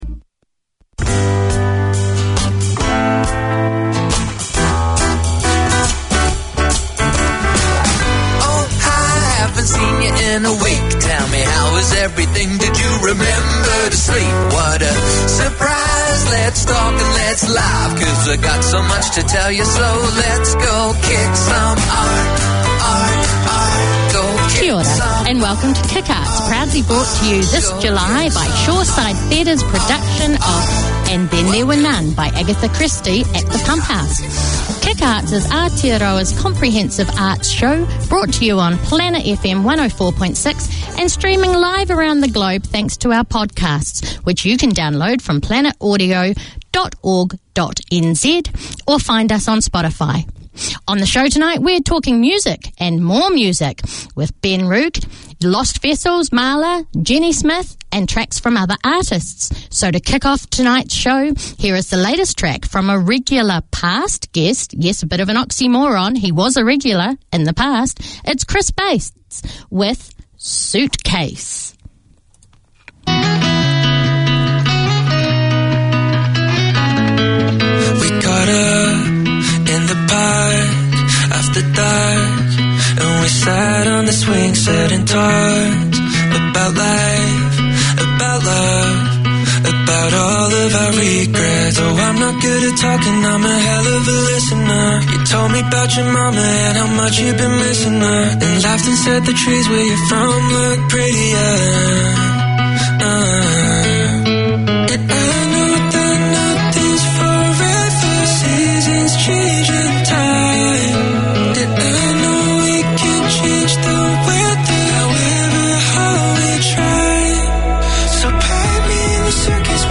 Pasifika Wire Live is a talanoa/chat show featuring people and topics of interest to Pasifika and the wider community.
Pasifika Wire 4:50pm SATURDAY Community magazine Language: English Pasifika Wire Live is a talanoa/chat show featuring people and topics of interest to Pasifika and the wider community.